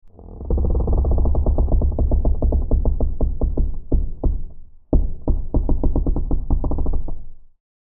دانلود آهنگ قایق 9 از افکت صوتی حمل و نقل
دانلود صدای قایق 9 از ساعد نیوز با لینک مستقیم و کیفیت بالا
جلوه های صوتی